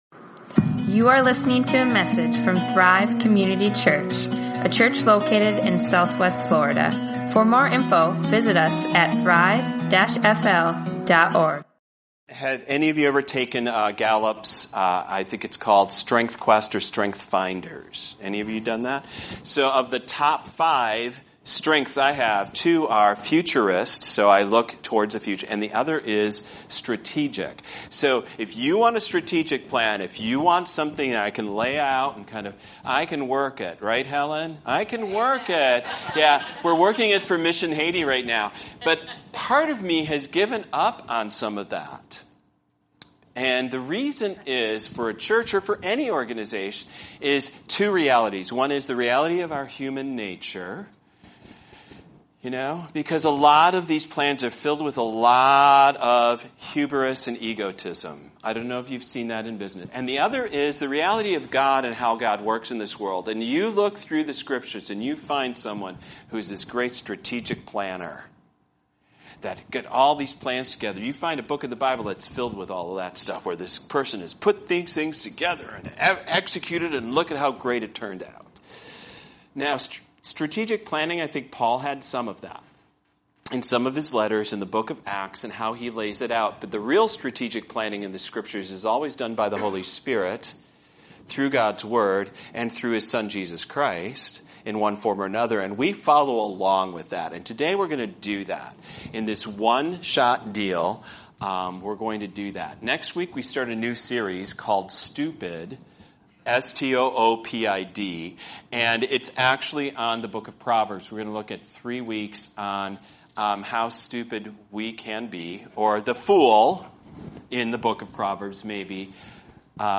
This Is My Year | Sermons | Thrive Community Church